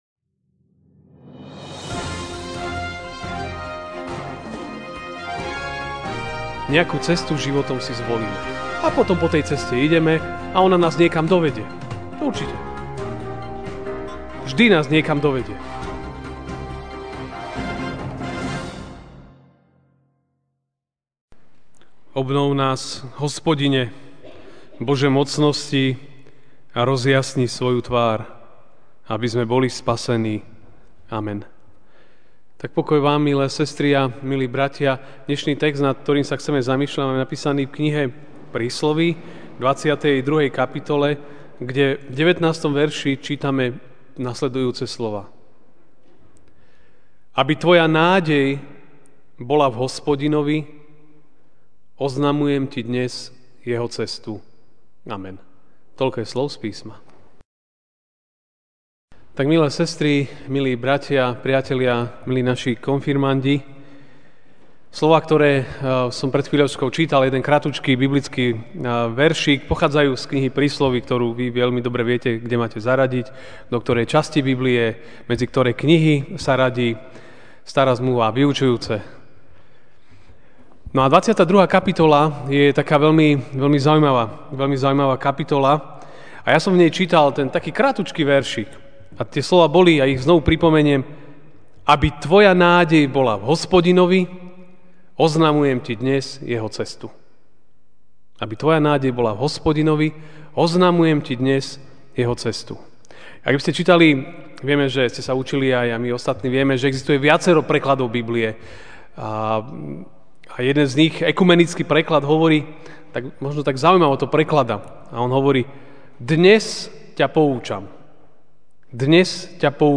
máj 28, 2017 Cesta MP3 SUBSCRIBE on iTunes(Podcast) Notes Sermons in this Series Večerná kázeň: Cesta (Príslovia 22, 19) Aby tvoja nádej bola v Hospodinovi, oznamujem ti dnes Jeho cestu.